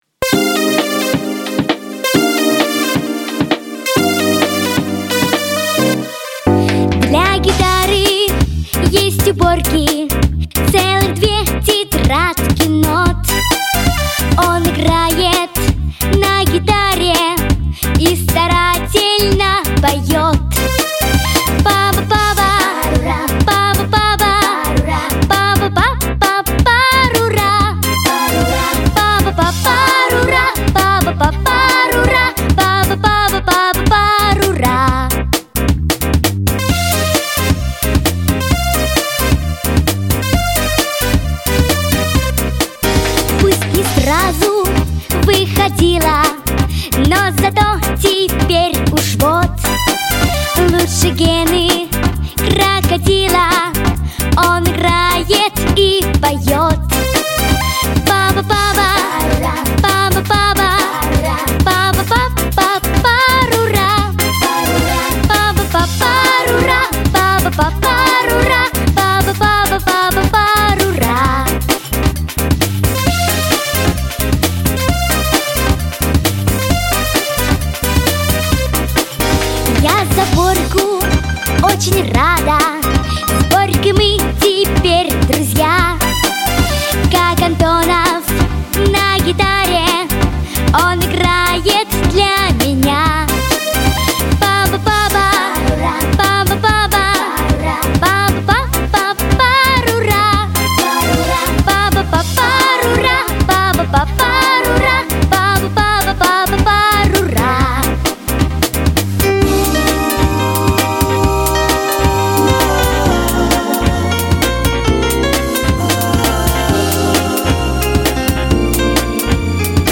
• Категория: Детские песни
Детская вокально-эстрадная студия